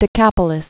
(dĭ-kăpə-lĭs)